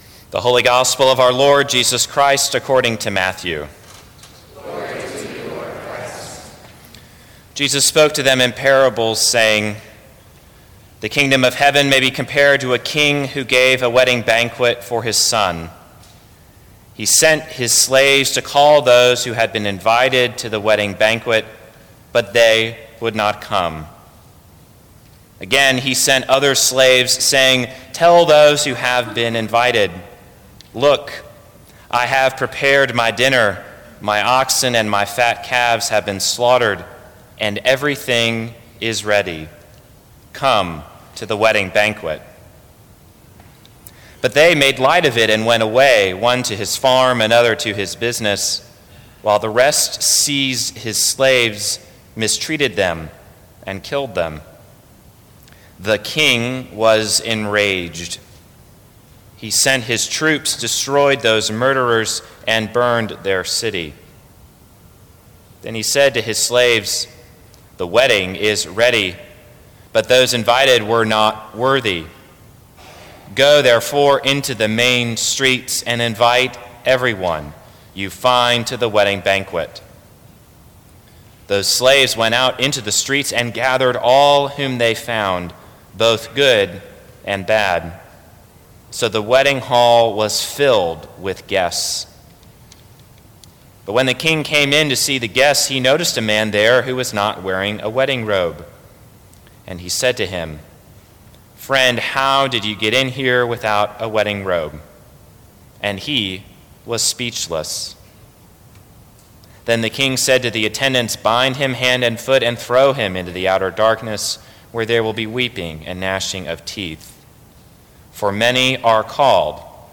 Sermons from St. Cross Episcopal Church Feast Oct 16 2017 | 00:16:23 1x 00:00 / 00:16:23 Subscribe Share Apple Podcasts Spotify Overcast RSS Feed Share Link Embed